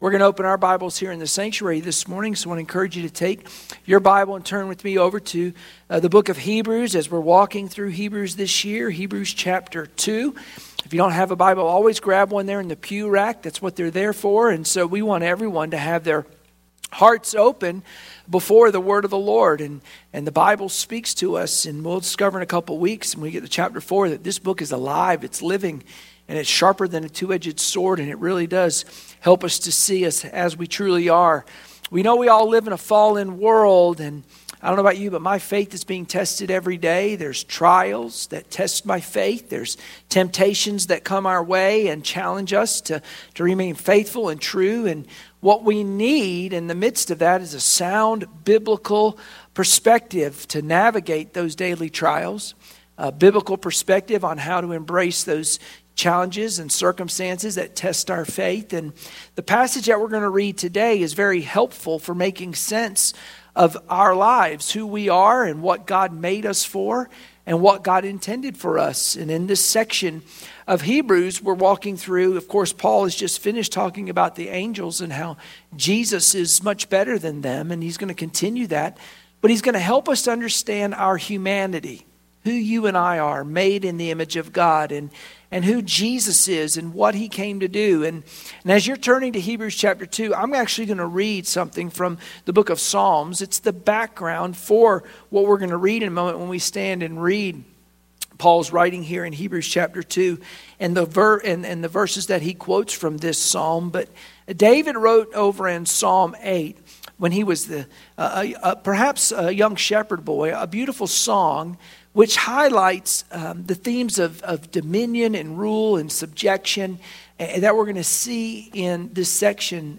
Sunday Morning Worship Passage: Hebrews 2:5-9 Service Type: Sunday Morning Worship Share this